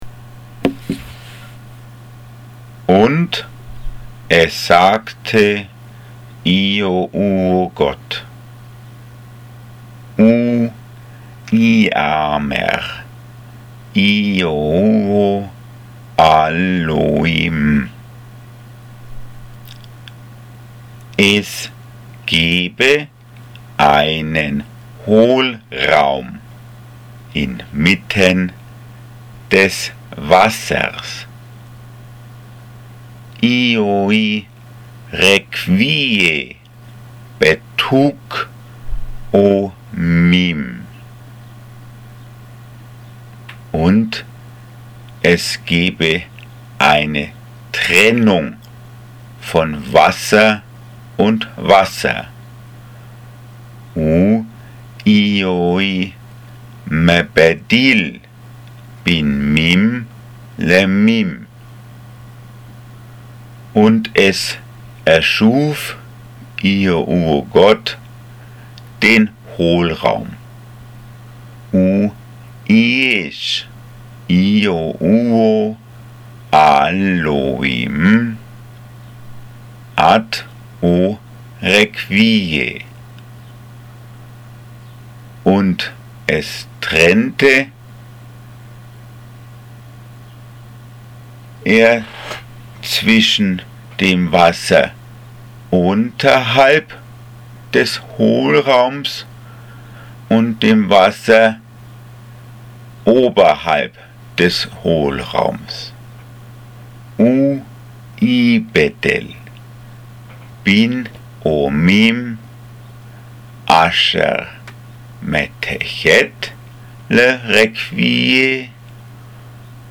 Hörbibel Deutsch Adamisch
Audiobibel_DeuAda_Gen_1_6_8.mp3